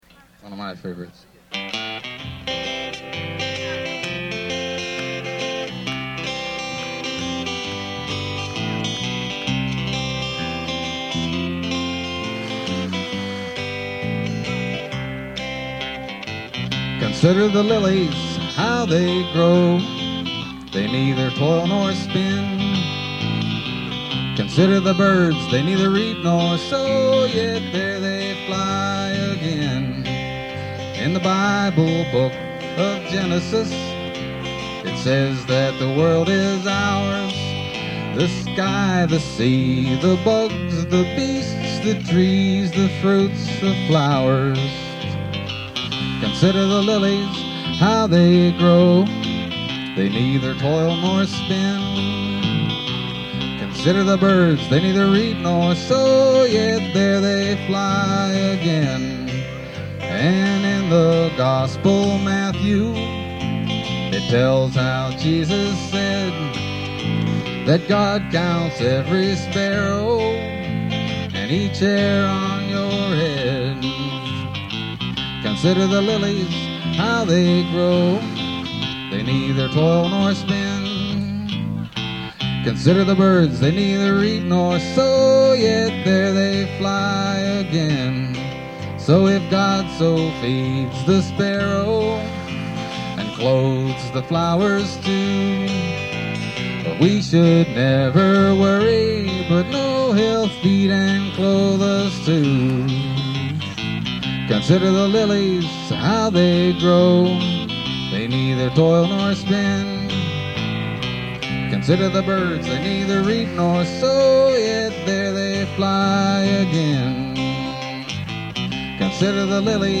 live at the BAM Picnic